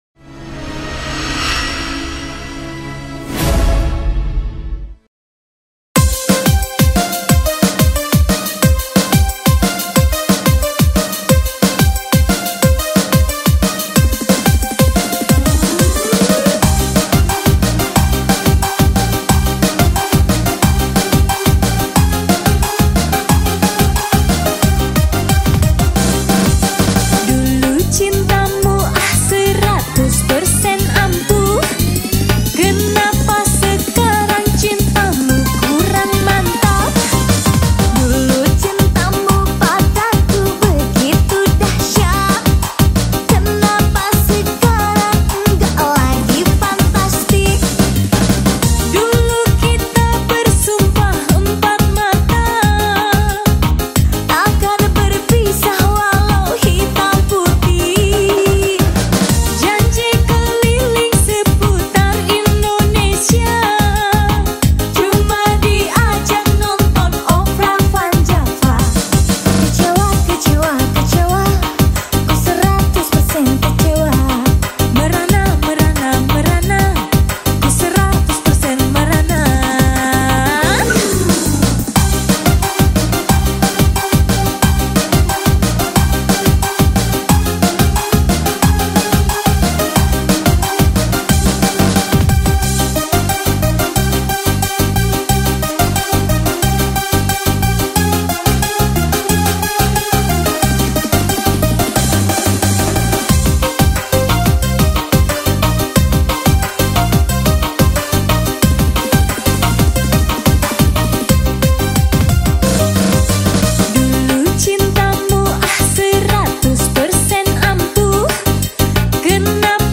Dangdut